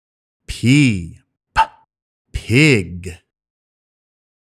音声を聴いて、このゲームの中で使われている単語をフォニックスの読み方で発音してみよう！
53.-P-Pig.mp3